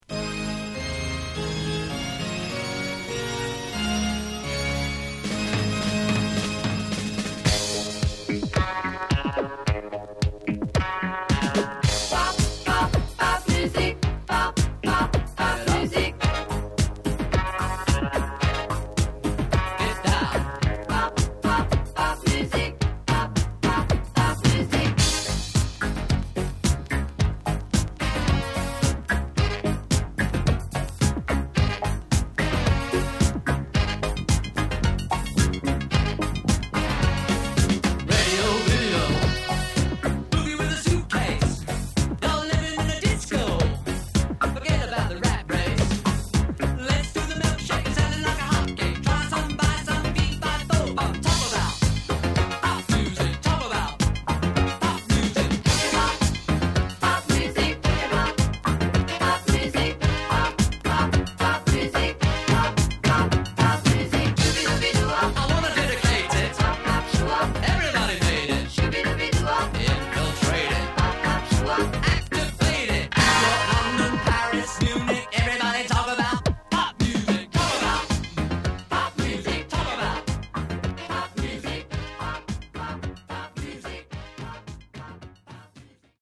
Genre: Techno/Synth Pop